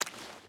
Water Walk 5.wav